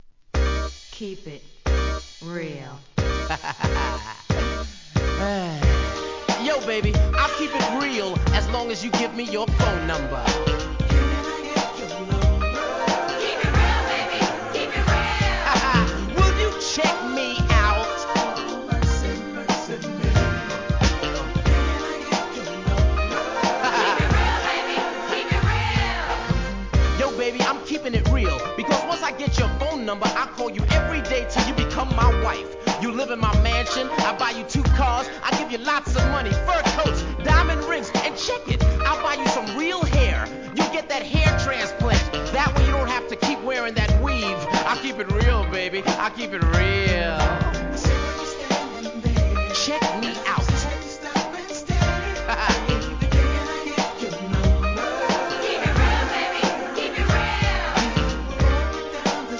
HIP HOP/R&B
1994年、硬派MIDナンバー!!